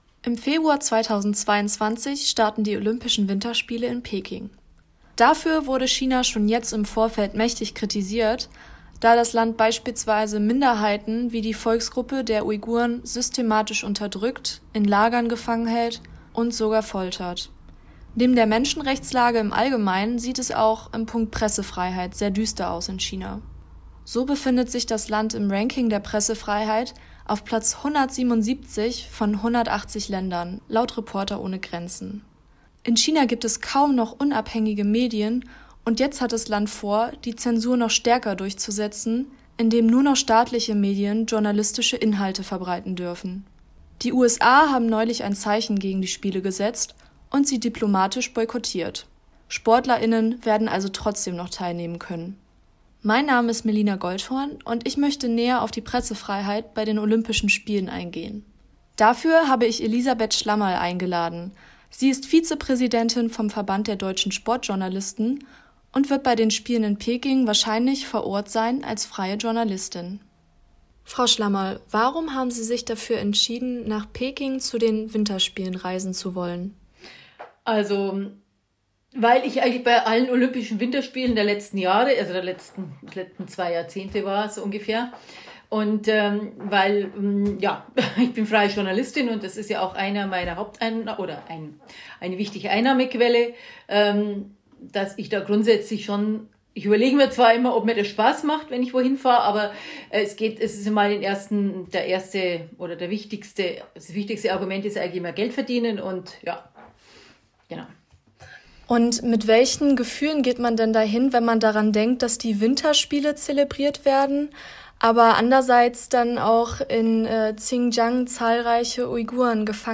Interview: Pressefreiheit bei den Olympischen Spielen – h²radio